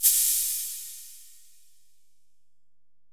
808LP70MAR.wav